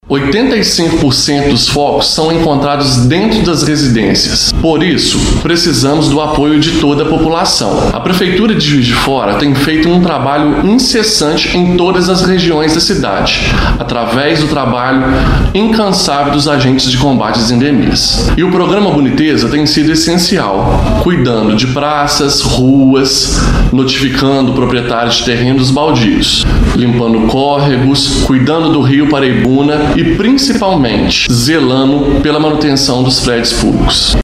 Conforme o subsecretário de Vigilância em Saúde, Jonathan Ferreira Tomaz, a maioria dos focos foram encontrados em residências.